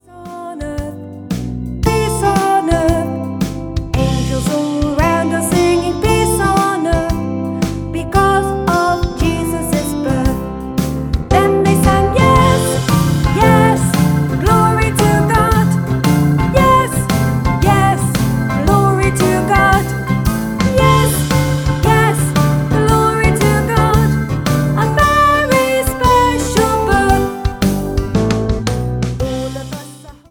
CD (Vocals and Backing Tracks)